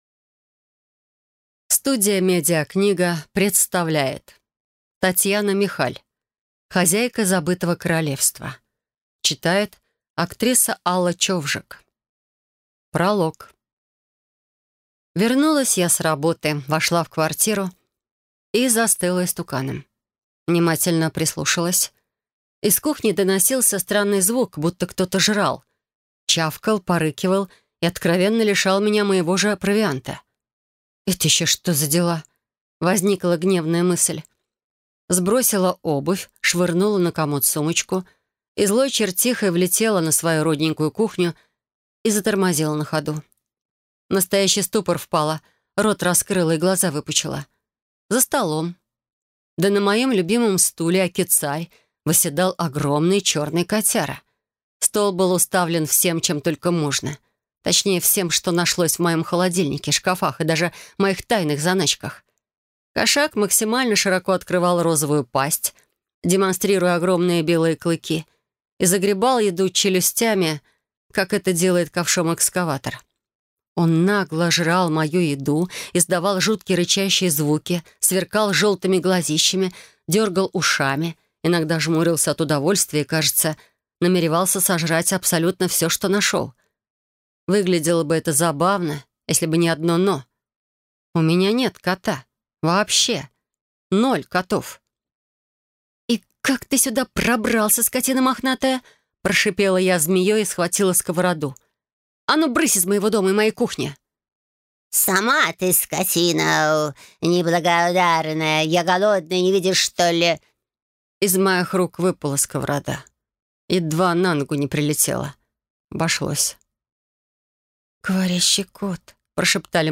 Аудиокнига «Хозяйка Забытого королевства».